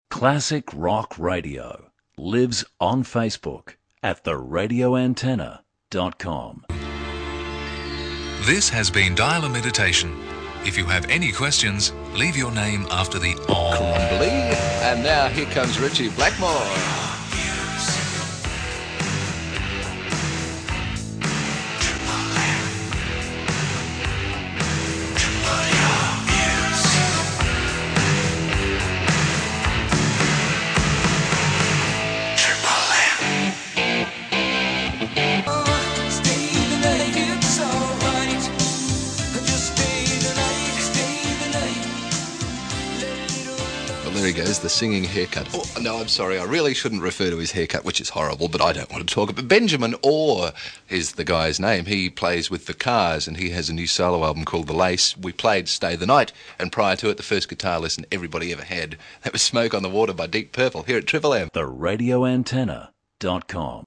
Effective use of the Triple Your Music segue is evident in this snapshot